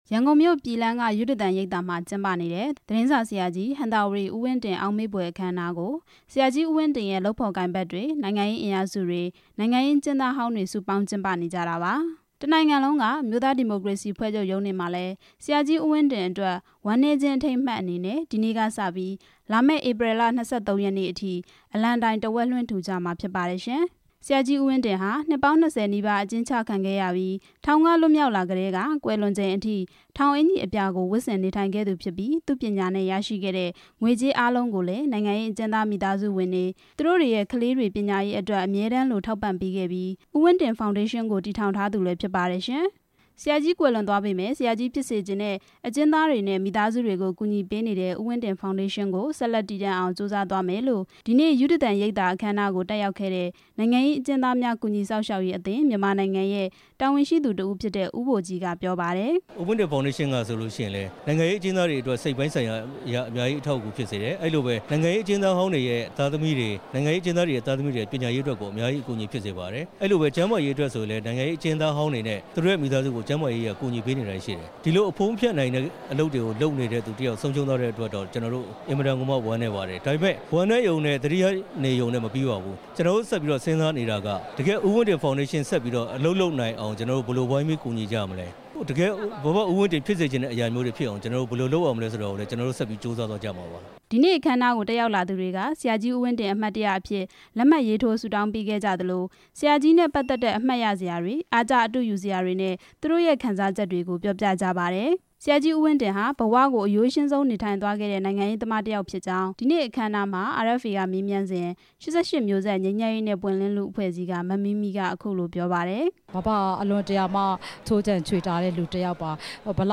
ဦးဝင်းတင် အောက်မေ့ဘွယ် အခမ်းအနားကို တက်ရောက်လာသူတွေရဲ့ ပြောပြချက်